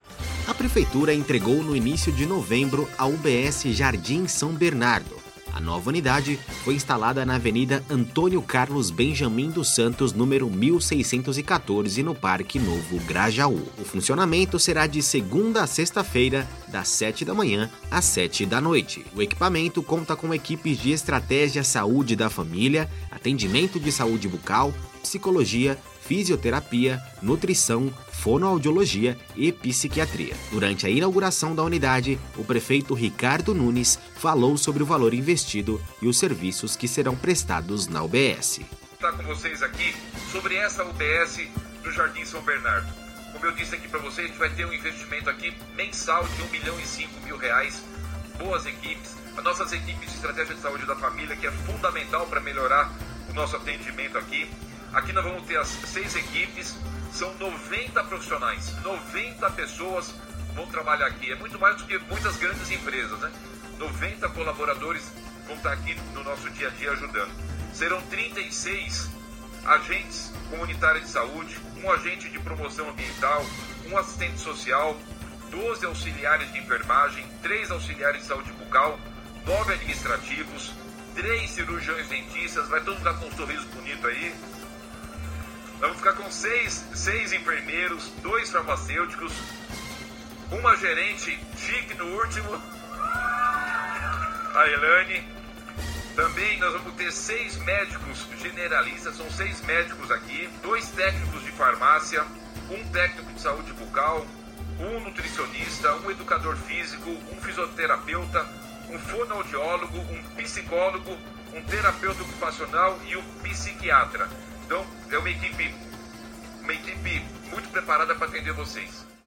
Durante a inauguração da unidade o prefeito Ricardo Nunes falou sobre o valor investido e os serviços que serão prestados na UBS.